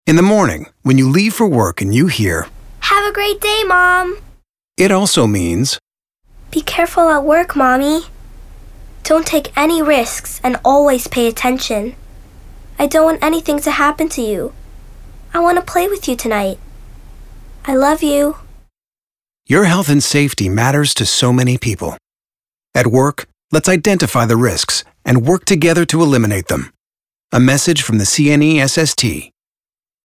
Commercial (CNESST) - EN